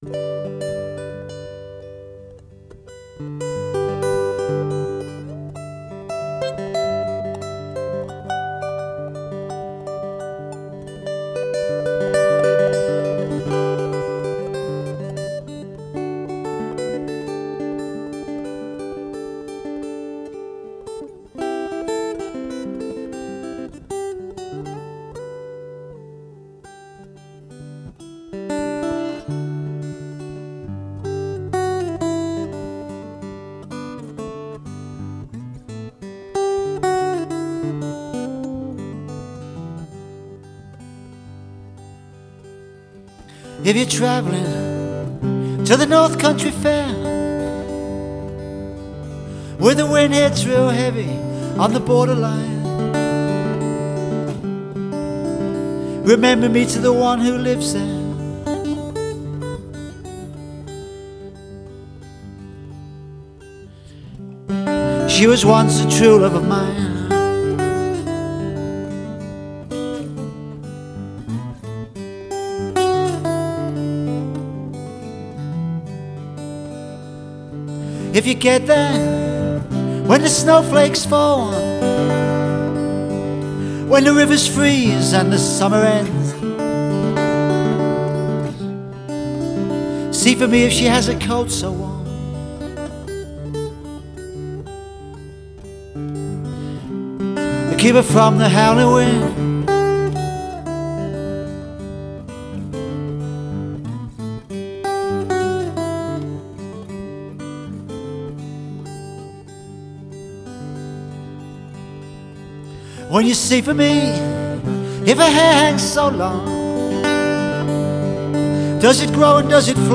Blues-Folk singer/songwriter/guitarist